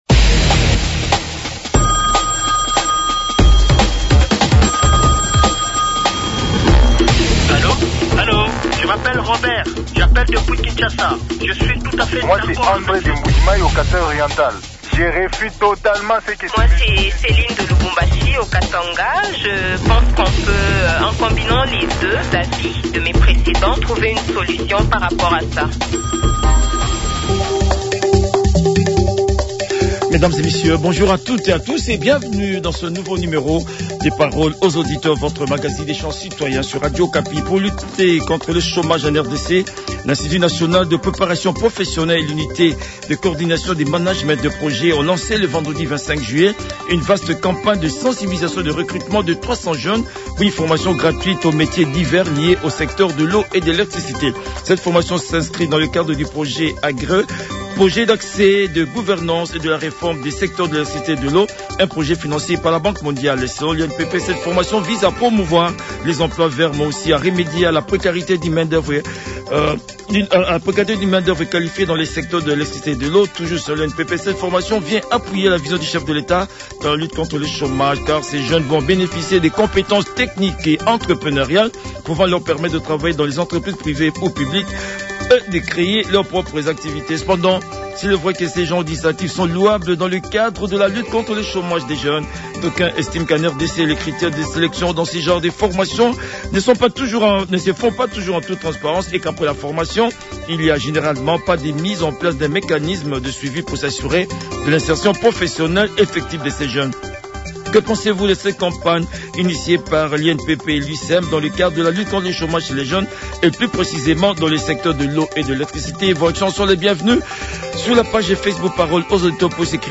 Les auditeurs ont débattu